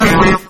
Grito de Bellsprout.ogg
Grito_de_Bellsprout.ogg.mp3